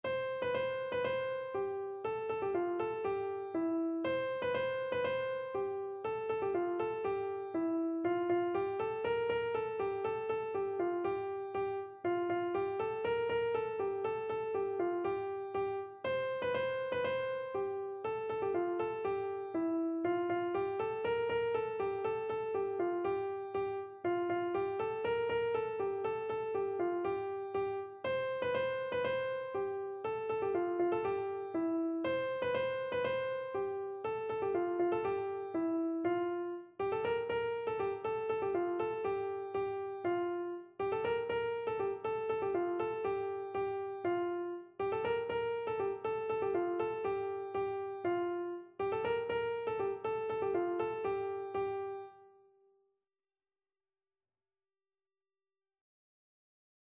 Traditional Music of unknown author.
Voice  (View more Intermediate Voice Music)
World (View more World Voice Music)